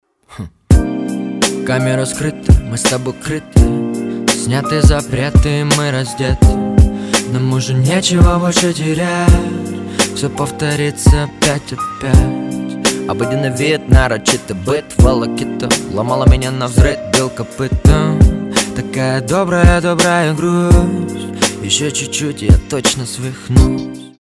• Качество: 320, Stereo
мужской вокал
лирика
спокойные
романтика
качает
приятный мотив